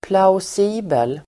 Ladda ner uttalet
Folkets service: plausibel plausibel adjektiv, plausible Uttal: [pla_os'i:bel] Böjningar: plausibelt, plausibla Definition: trolig plausible adjektiv, rimlig , trolig , plausibel Förklaring: appearing convincing